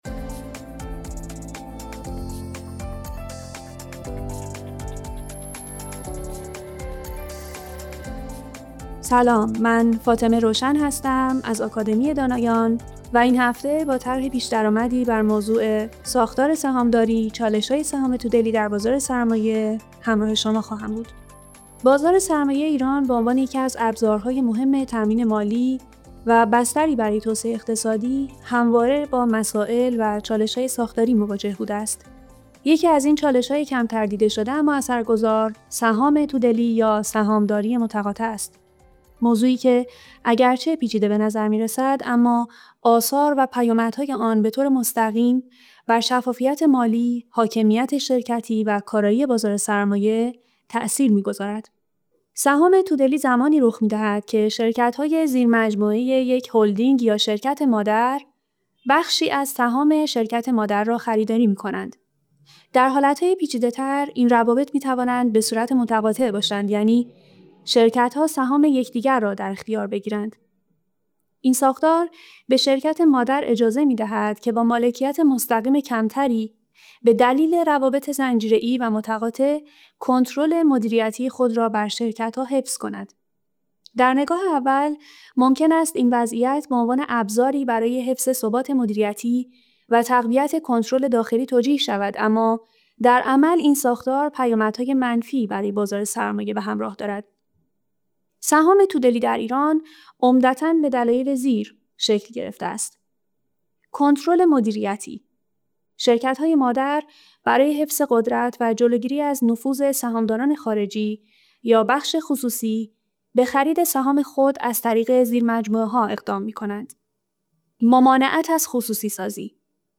فایل صوتی مناظره